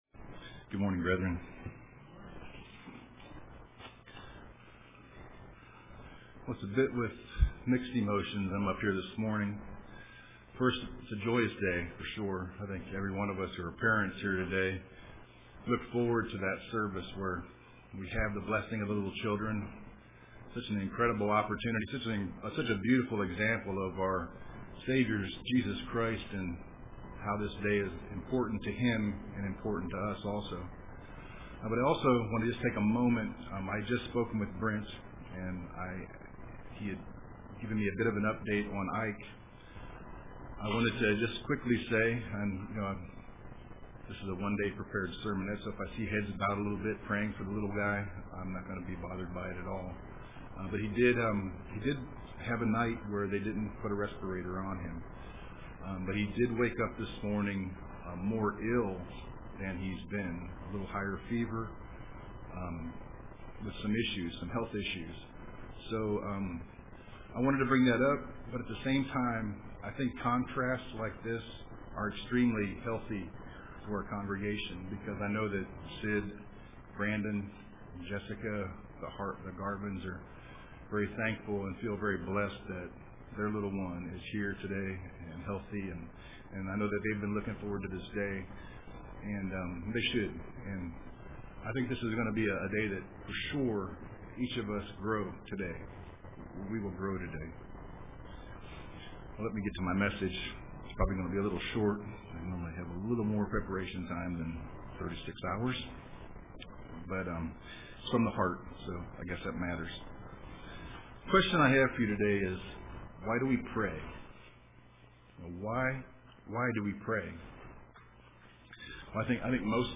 Print Why do we Pray UCG Sermon